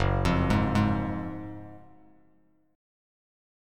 F#M7sus2 Chord